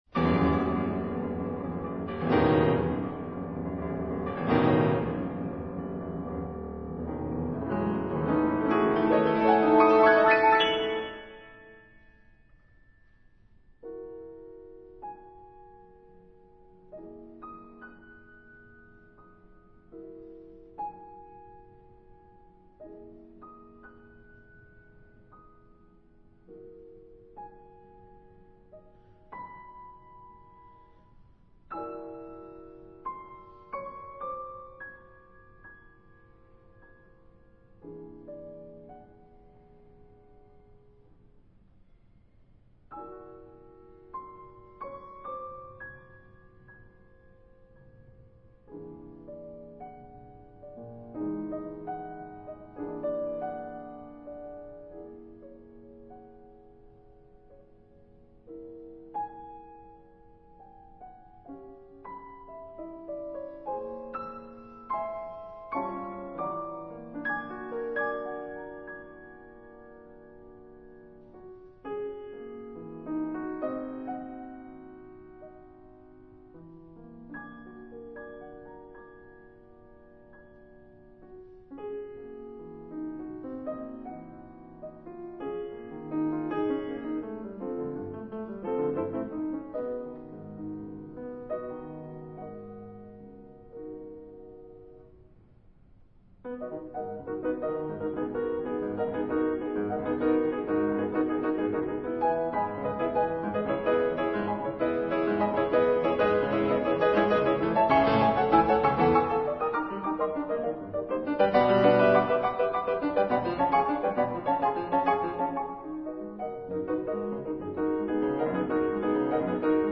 piano A. Skryabin Sonata No. 5